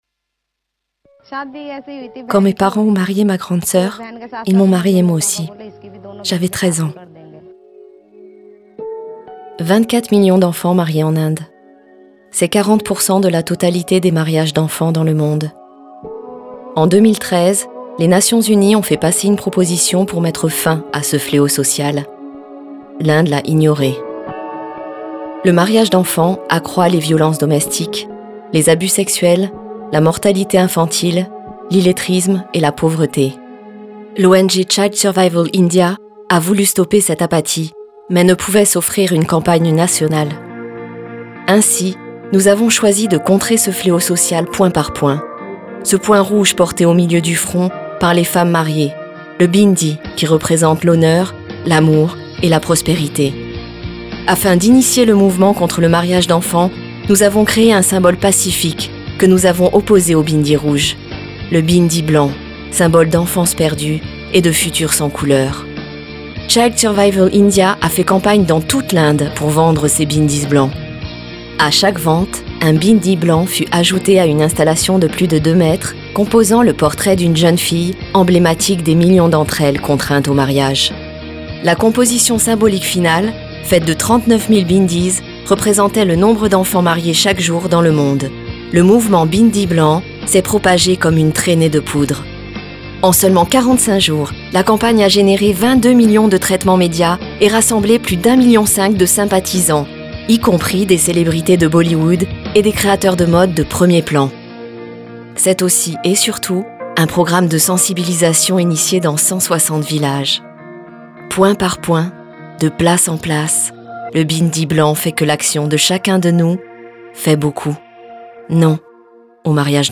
Film Institutionnel 2mn
Voix Off